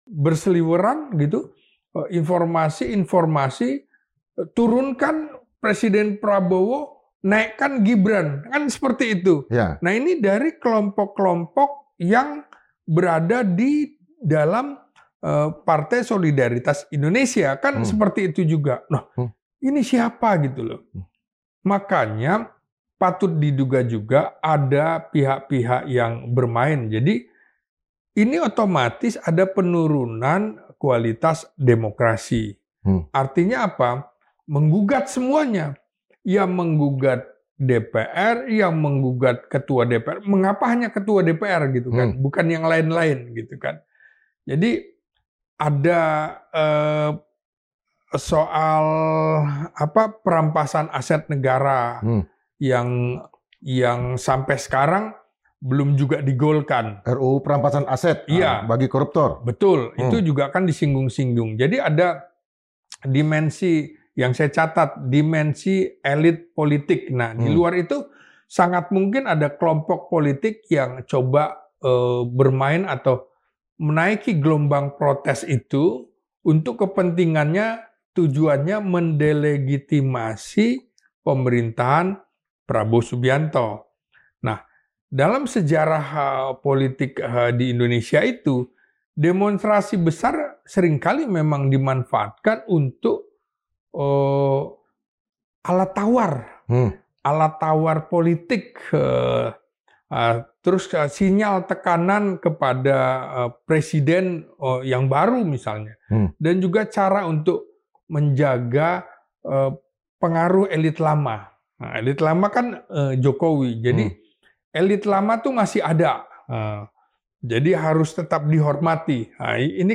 Podcast Madilog Forum Keadilan berikut ini menjawab pertanyaan-pertanyaan tersebut bersama narasumber terpercaya.